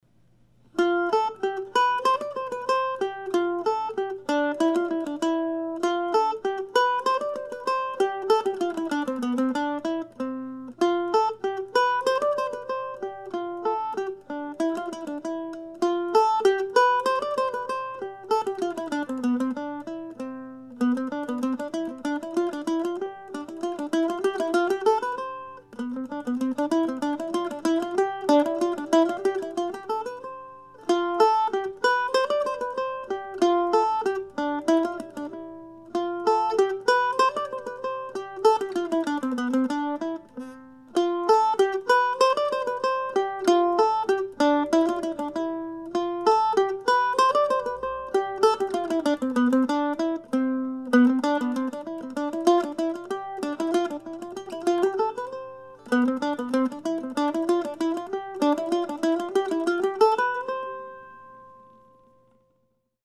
Here it is presented as a solo piece in the key of C.